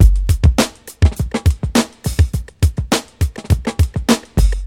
• 103 Bpm Breakbeat Sample G Key.wav
Free breakbeat sample - kick tuned to the G note. Loudest frequency: 995Hz
103-bpm-breakbeat-sample-g-key-12R.wav